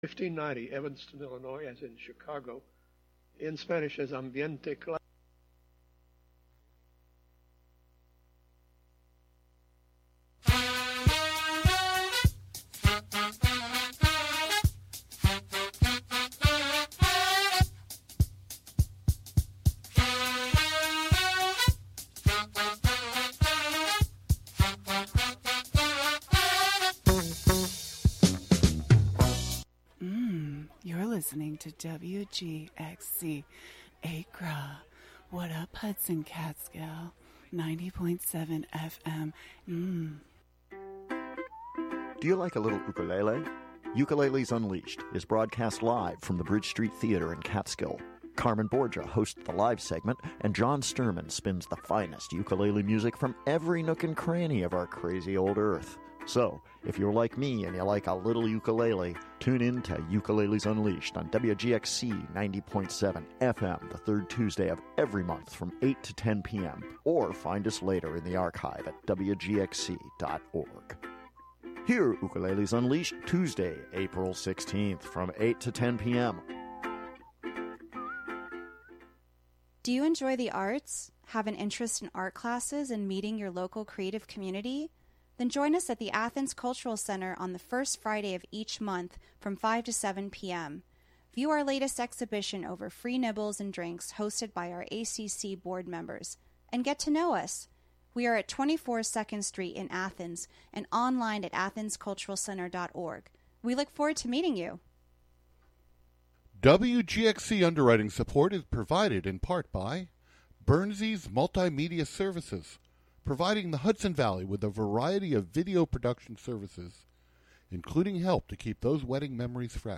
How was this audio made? Saturday the emphasis is more on radio art, and art on the radio.